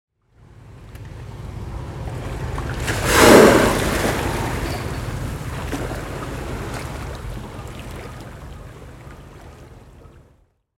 whale_sound.mp3